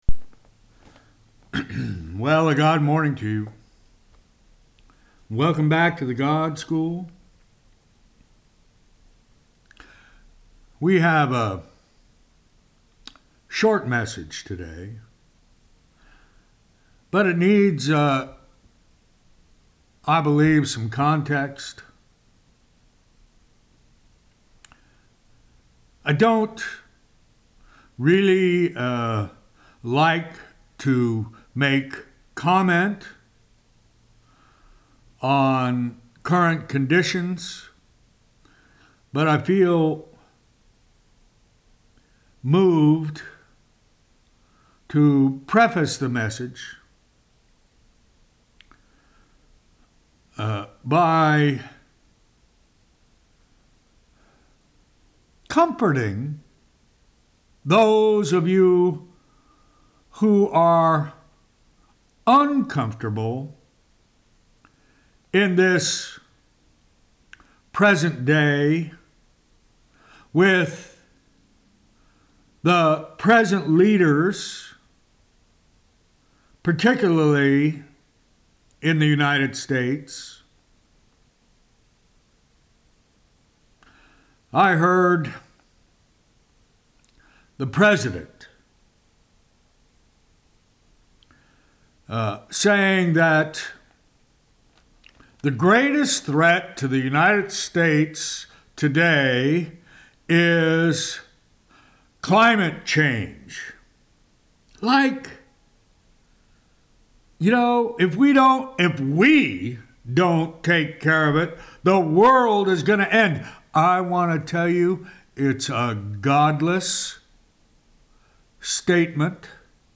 Weekly Teaching